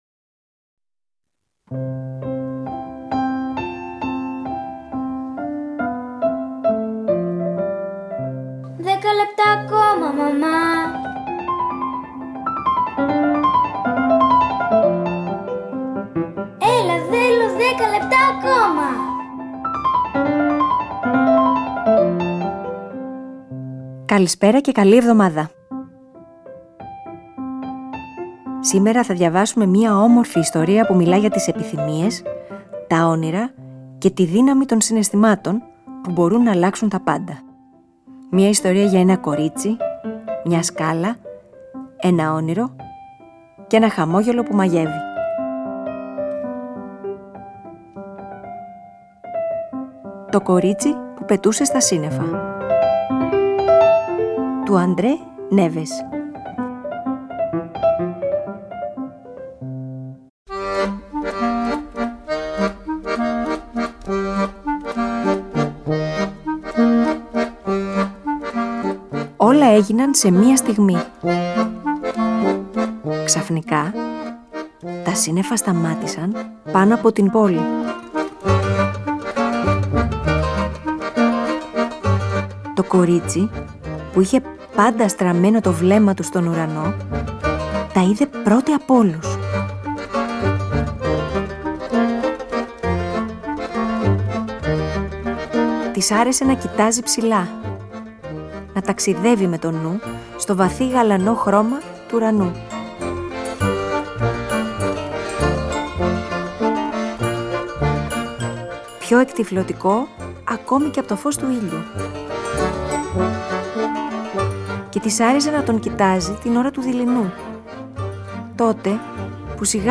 Σήμερα Δευτέρα, θα διαβάσουμε ένα ονειρικό παραμύθι για μια ιστορία, ονείρων, αγάπης, τρυφερής συνύπαρξης και αποδοχής.